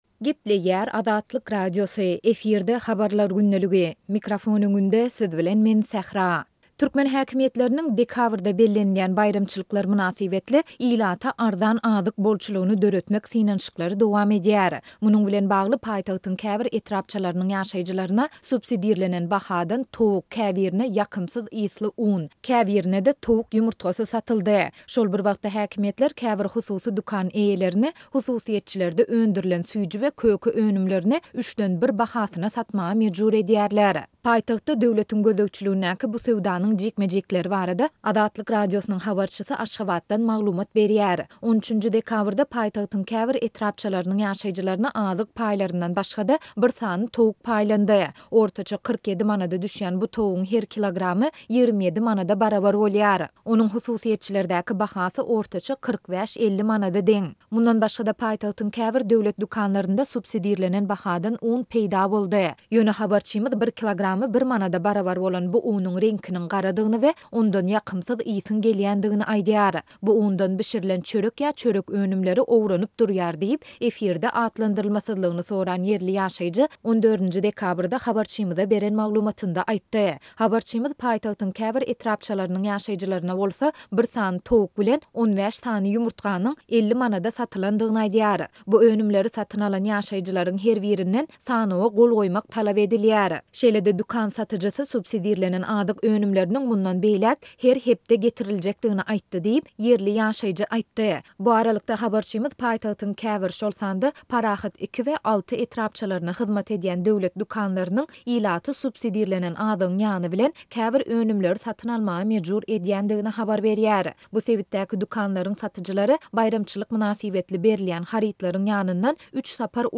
Munuň bilen bagly, paýtagtyň käbir etrapçalarynyň ýaşaýjylaryna subsidirlenen bahadan towuk, käbirine "ýakymsyz ysly" un, käbirine-de towuk ýumurtgasy satyldy. Şol bir wagtda, häkimiýetler käbir hususy dükan eýelerini süýji we köke önümlerini hakyky bahasynyň üçden bir bölegine satmaga mejbur edýärler. Paýtagtda döwletiň gözegçiligindäki bu söwdanyň jikme-jiklikleri barada Azatlyk Radiosynyň habarçysy Aşgabatdan maglumat berýär.